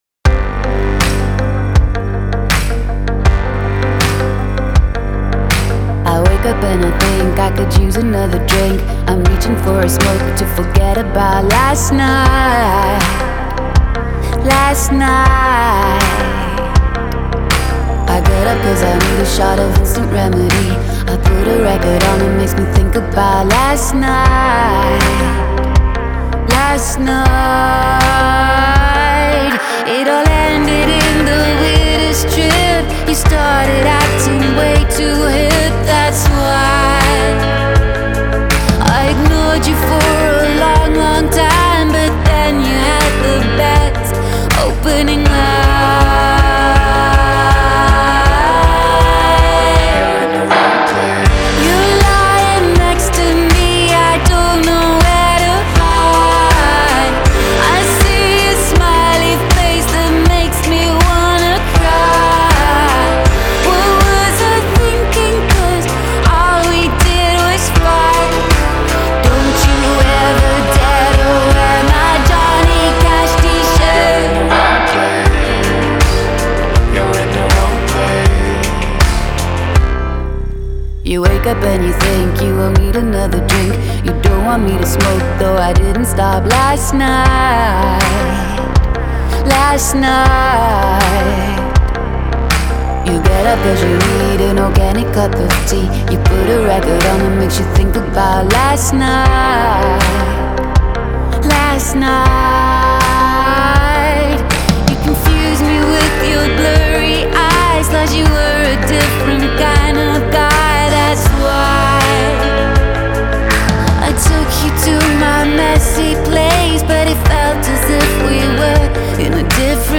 атмосферная и меланхоличная песня бельгийской группы